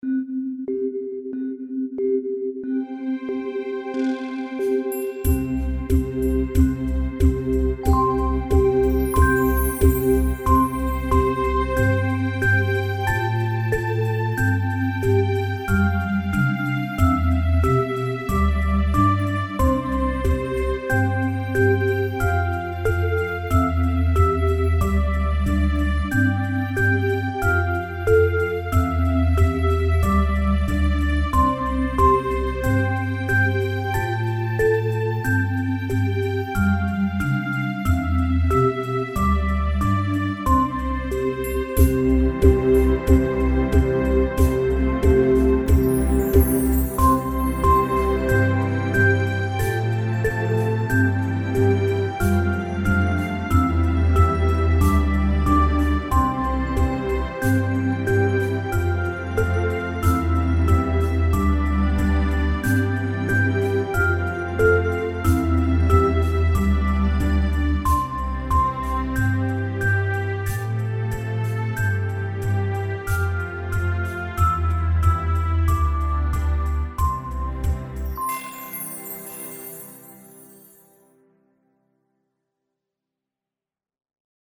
Lullaby, England, U.K.
Karaoke Songs for Kids
instrumental
4/4 – 96 bpm